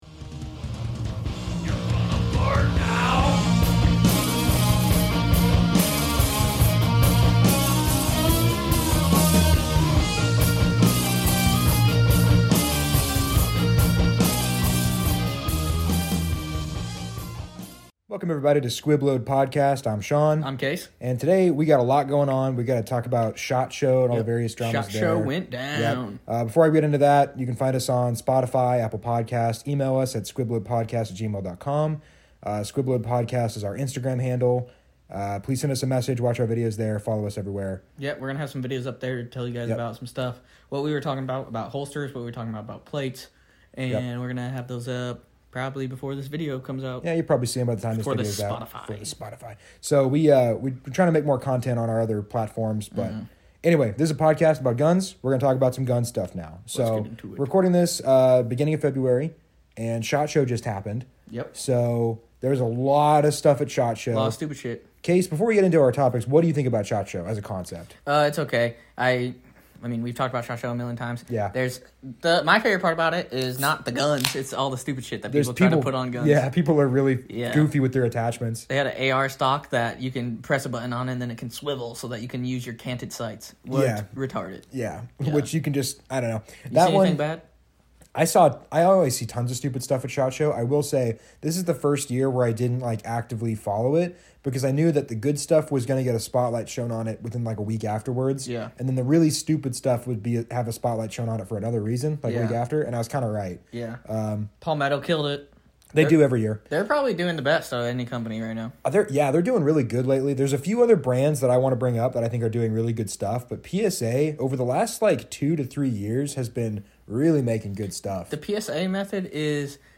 Don't miss two gun nerds losing their temper on this weeks episode of Squib Load!